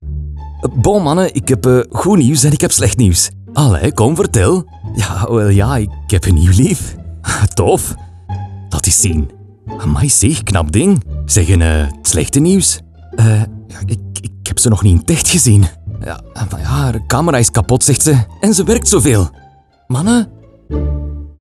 Male
Adult (30-50)
Flemish voice talent, characterized by a warm and friendly voice with clear articulation, creating the right atmosphere and achieving the perfect tone for each project.
Words that describe my voice are warm, deep, corporate.
0703FLEMISH_Conversational.mp3